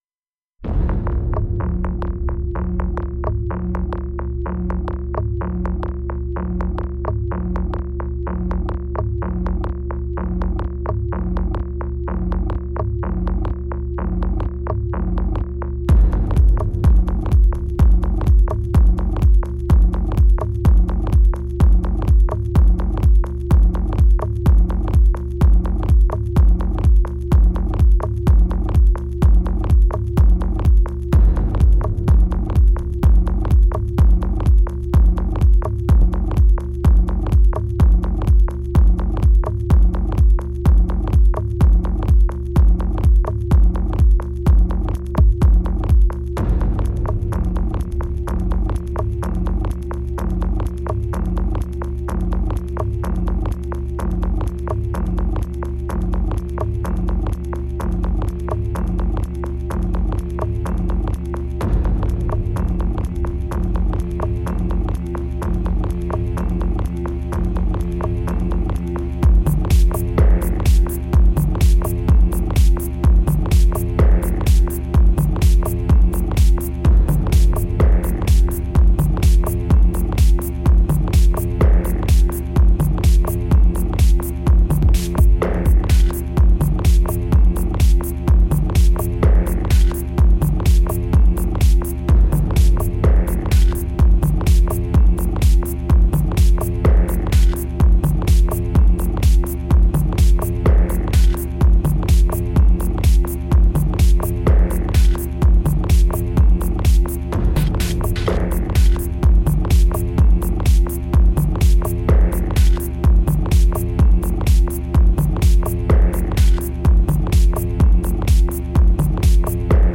Kicks and Hats have your back in difficult times